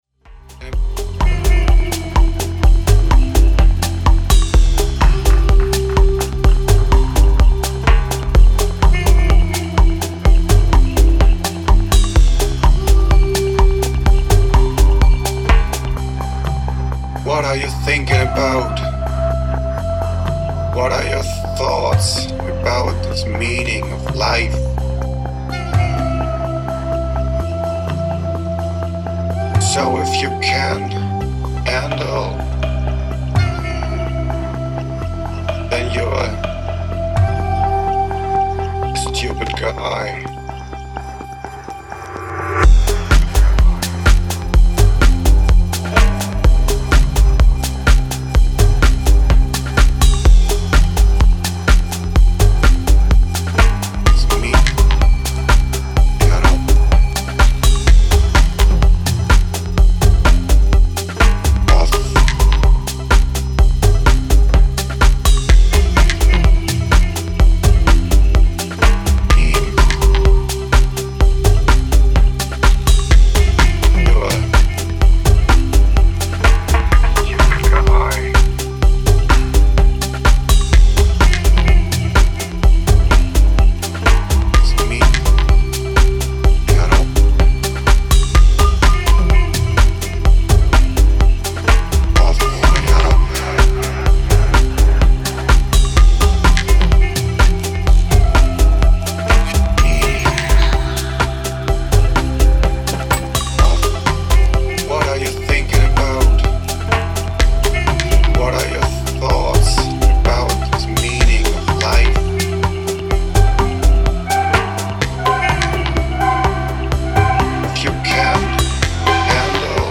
Style: Tech House